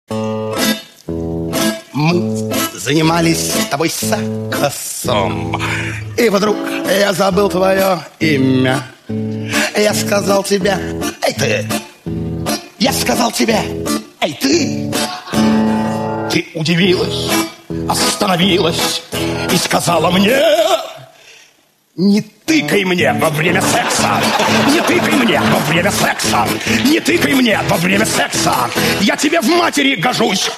гитара
мужской голос
цикличные